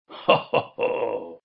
Звук смеха Бена